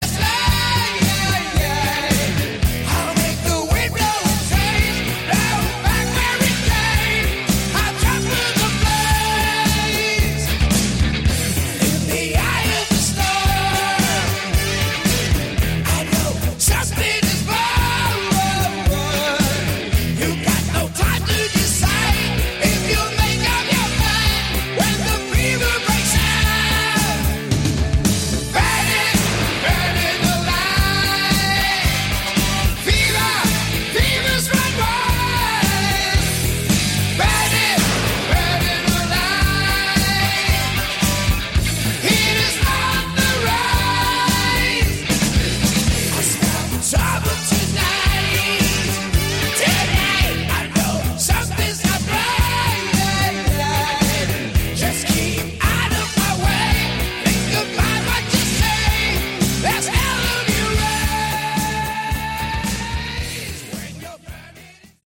Category: Hard Rock
lead and backing vocals
keyboards
guitar
drums